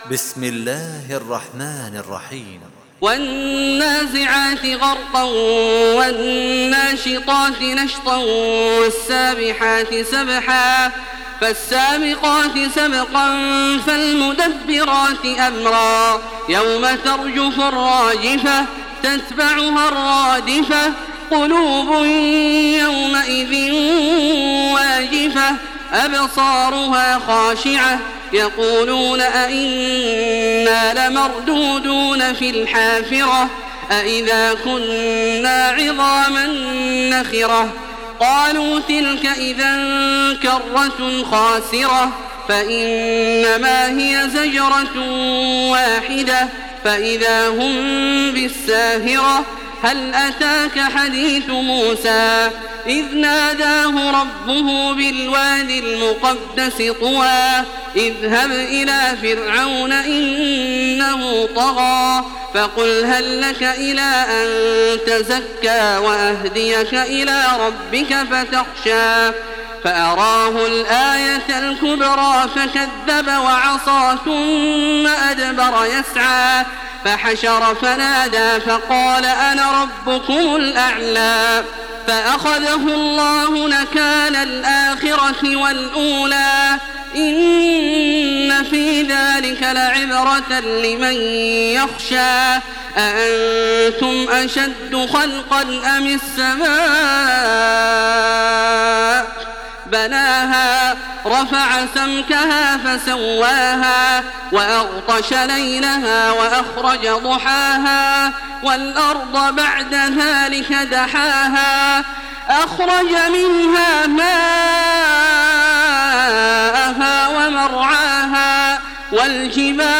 Makkah Taraweeh 1427
Murattal